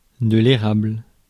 Ääntäminen
IPA : /meɪ.pəl/